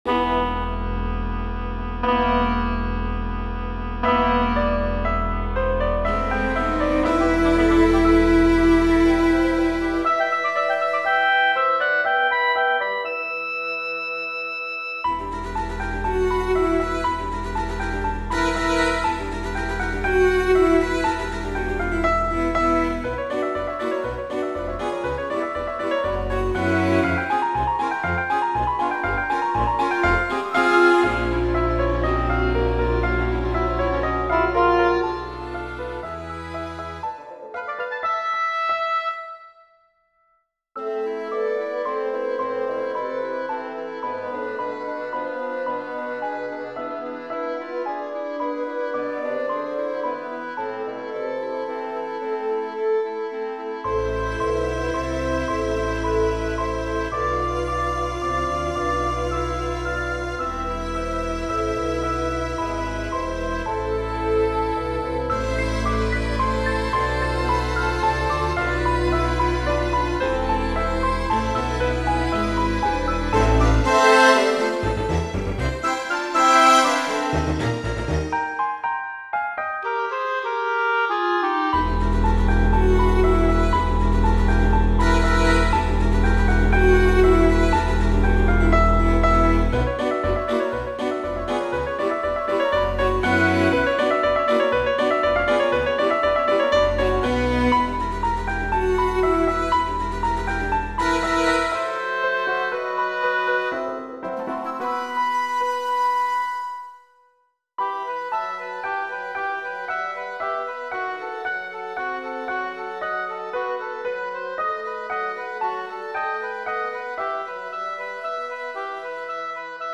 (e-mol )